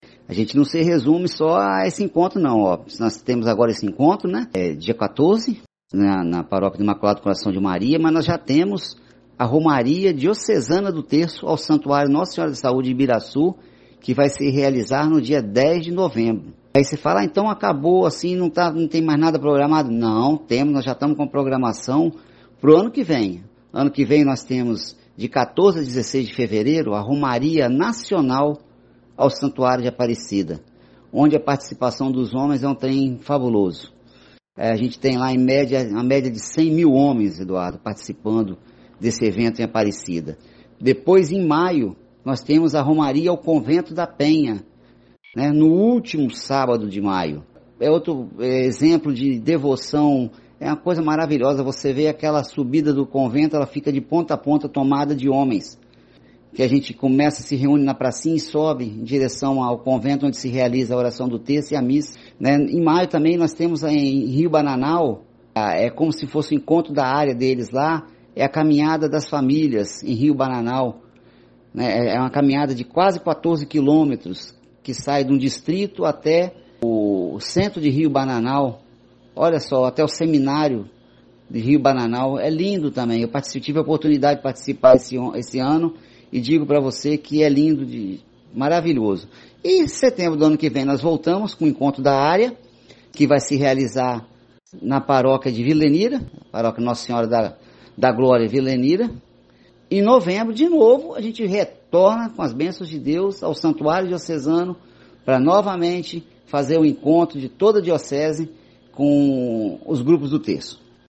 Pela primeira vez, adotamos o formato de entrevista com respostas em áudio (mp3).